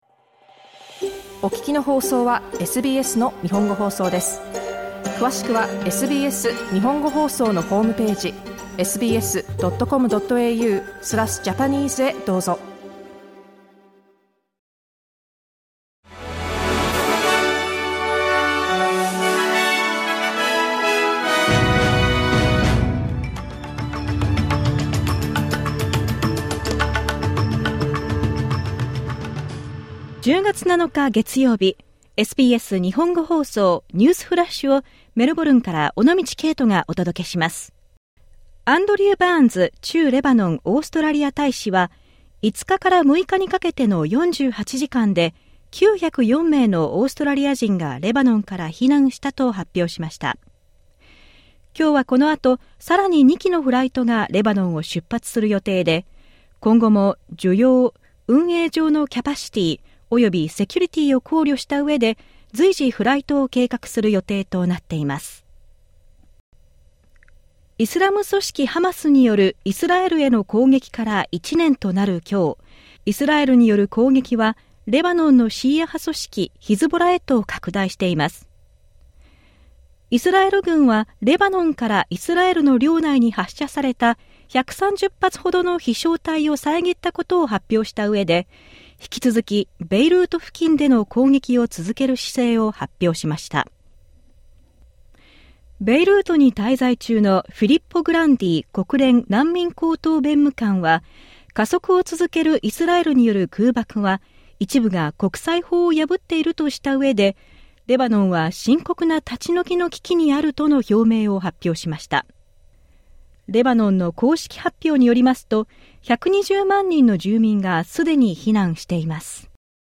SBS日本語放送ニュースフラッシュ 10月7日月曜日